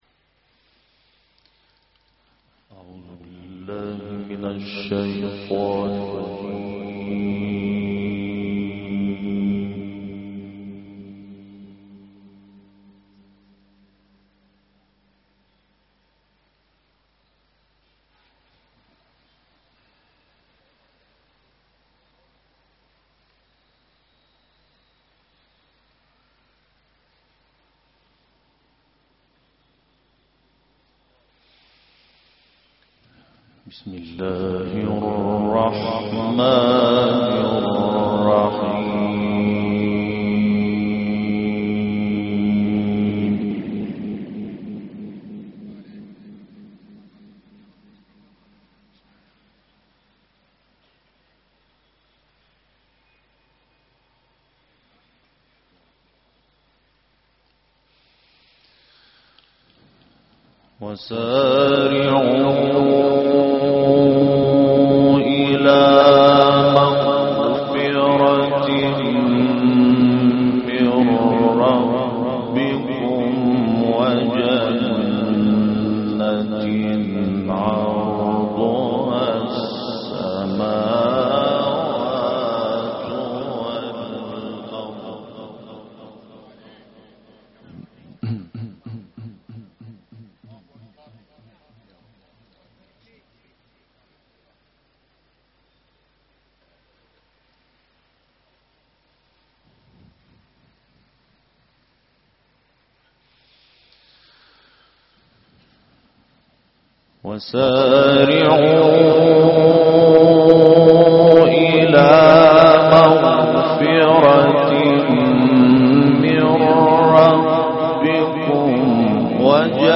تلاوت سوره آل‌عمران با صوت «محمود شحات انور»
گروه شبکه اجتماعی: تلاوت آیاتی از سوره مبارکه آل‌عمران با صوت محمود شحات انور ارائه می‌شود.